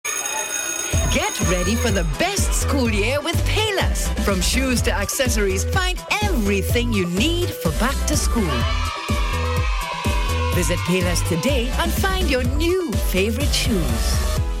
Top 5 Radio Ads
Their well-crafted, catchy 14 second voice over Ad earns them the fourth Top Ad spot.